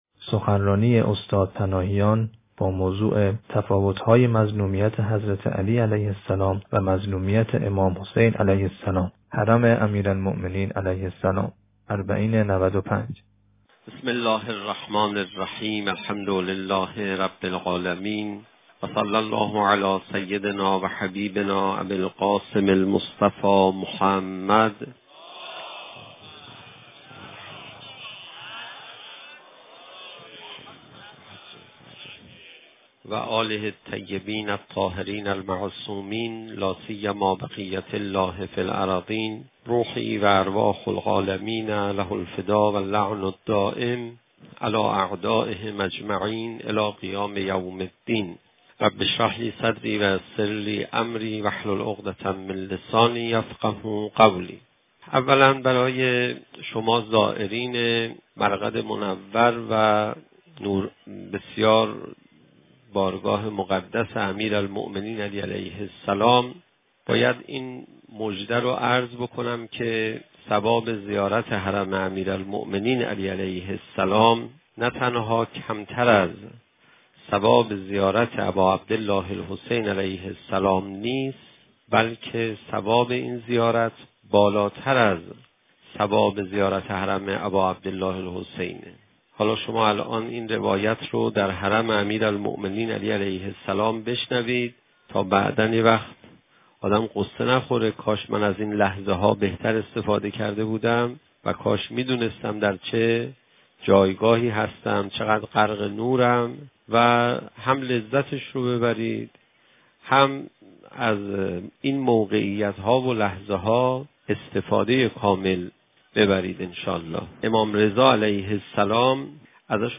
صوت | تفاوت ‌ های مظلومیت حضرت علی(ع) و مظلومیت امام حسین(ع) (نجف اشرف - حرم حضرت امیرالمؤمنین(ع) - اربعین 95 - 1 جلسه)